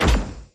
快速模式下，盘面整体下落音效.mp3